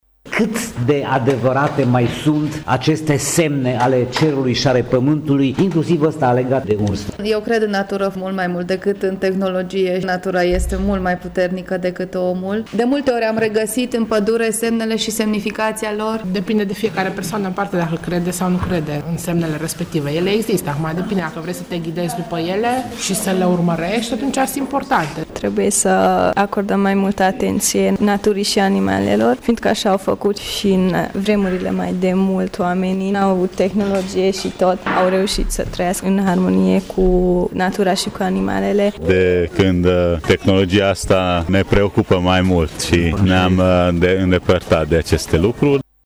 Vremea frumoasă a determinat mulți târgumureșeni cu copiii lor, să fie prezenți azi la Grădina Zoologică Tg. Mureș.